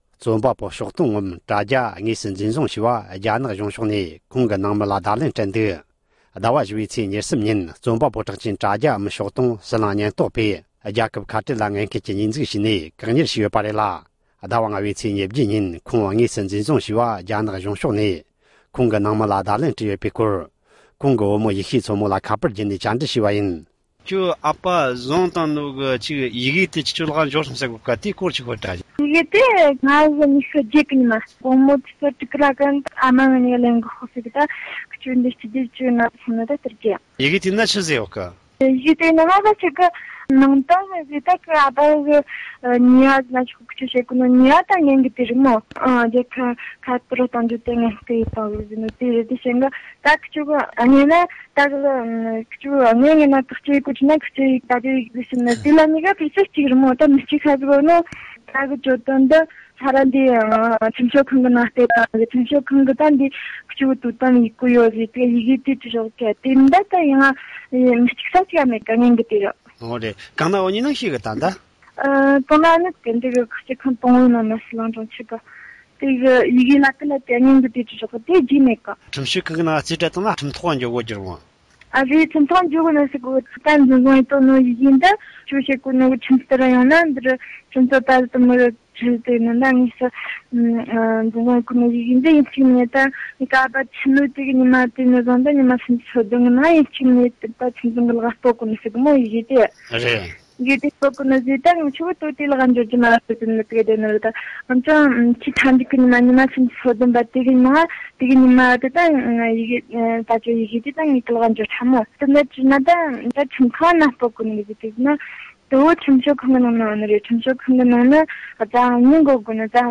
interview
བཅར་འདྲི།